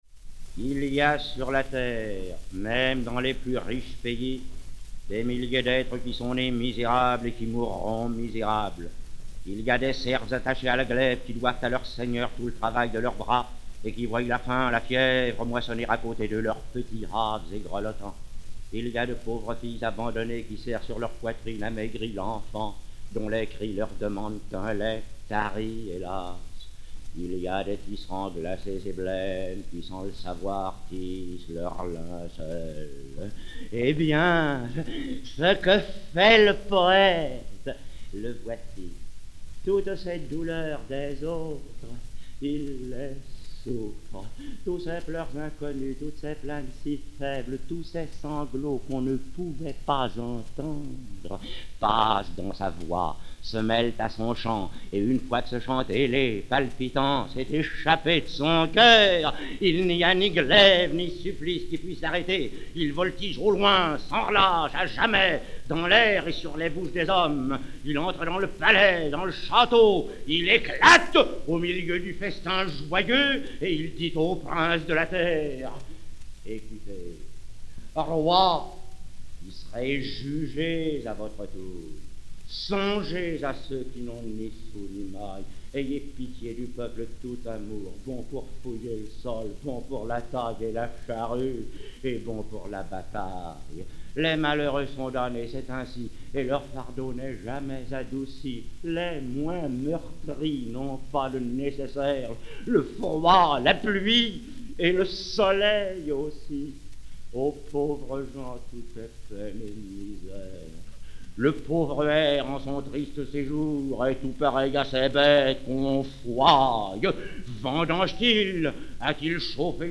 Georges Berr, sociétaire de la Comédie-Française